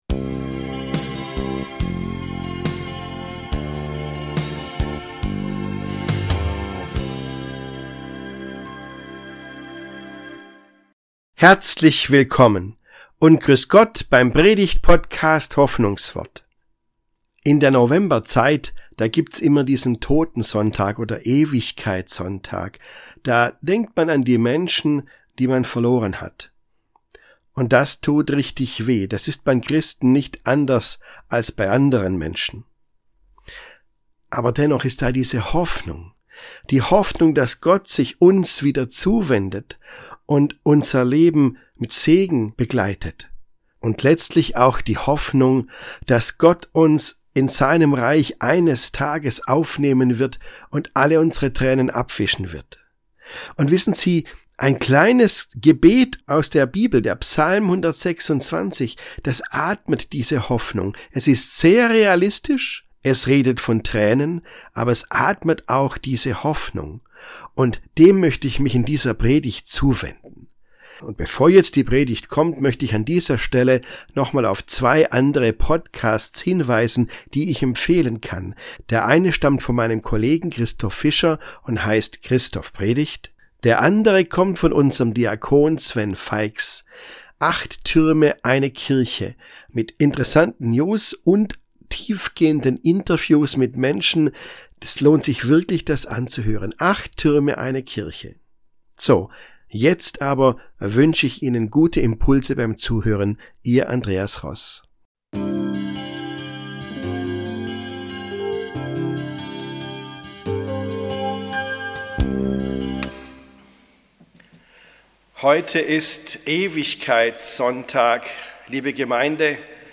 Ewigkeitssonntag: Mit Tränen säen, mit Freuden ernten ~ Hoffnungswort - Predigten